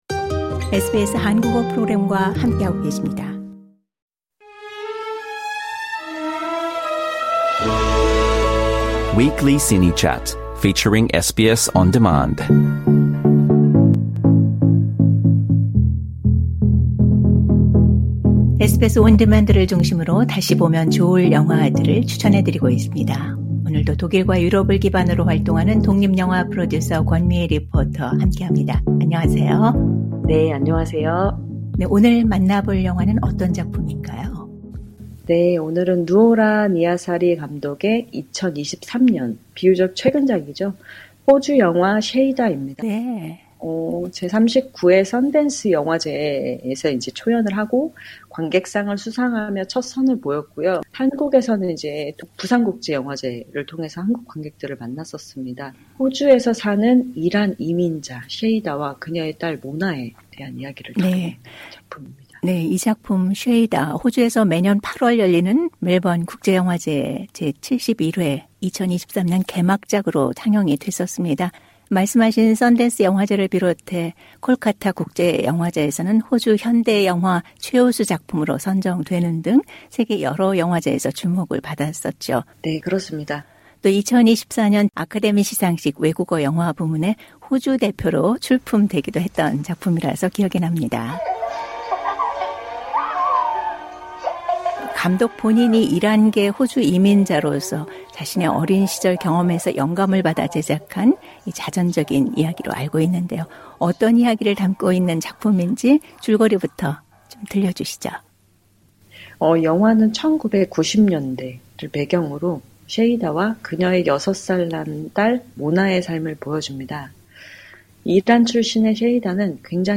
Shayda Trailer Audio Clip